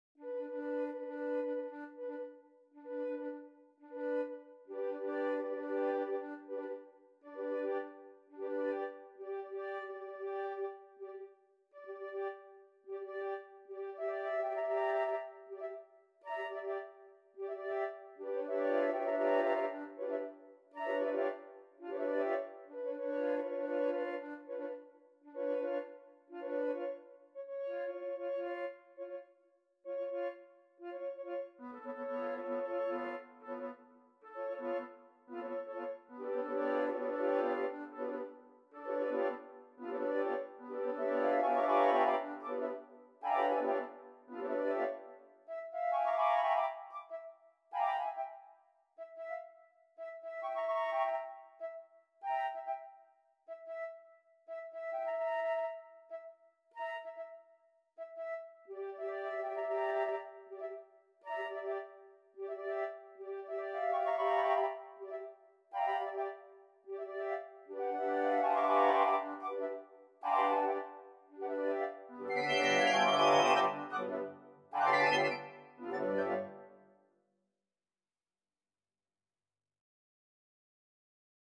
8 piccolo, 16 C-flutes, 4 alto flutes, 4 bass flutes